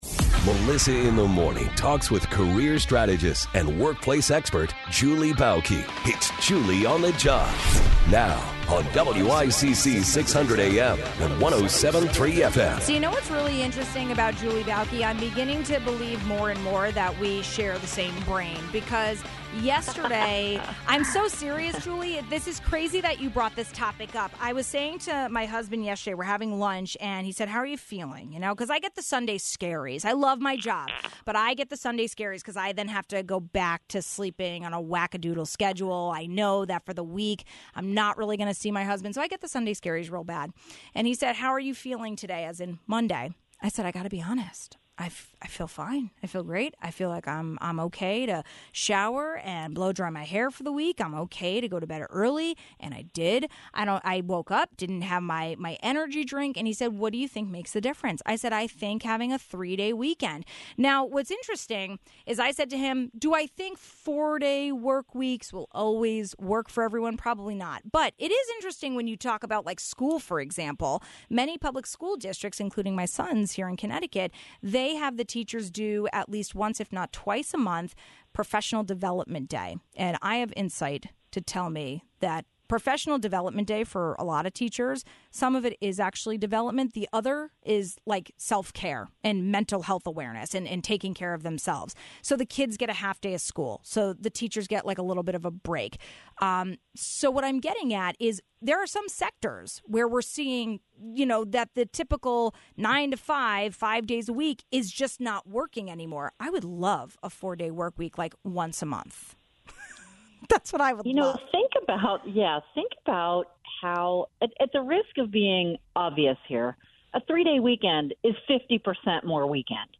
But the bigger question should be: why don’t managers trust their team more to try new programs? We looked at a recent article and talked about it in length with career strategist